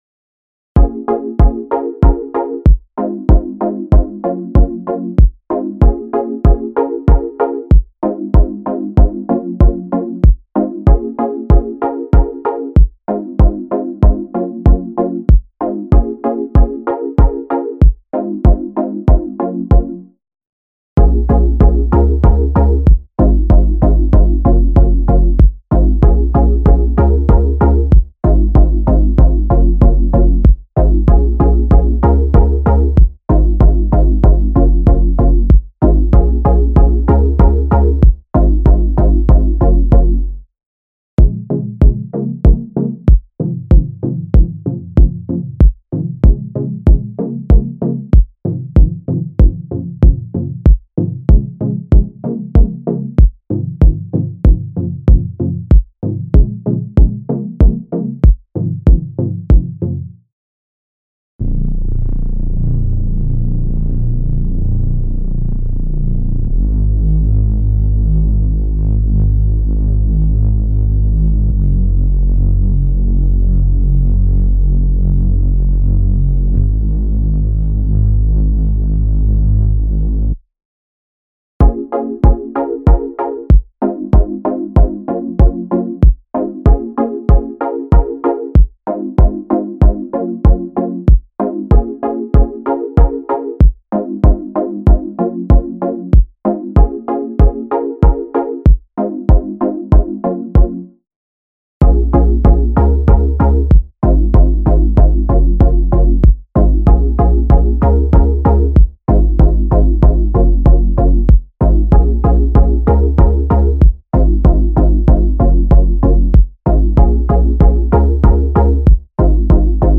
Hip-Hop Instrumentals